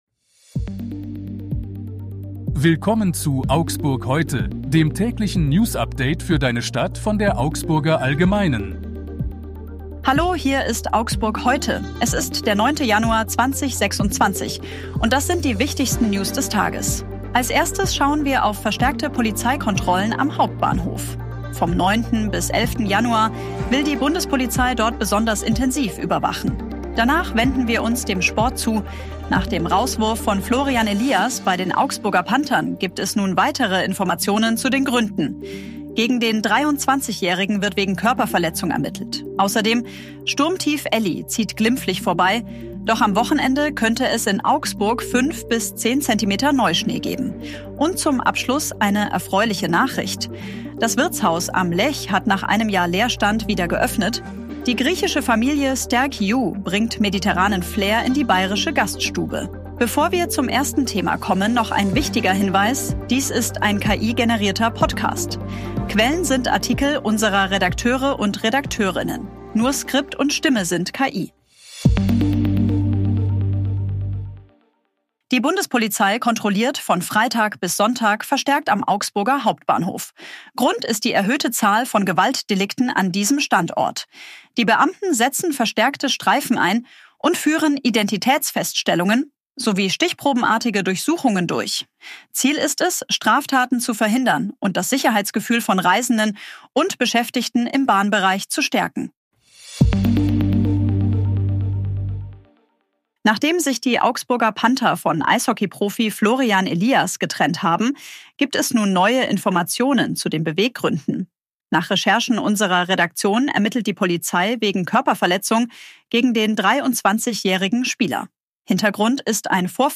Lech Dies ist ein KI-generierter Podcast.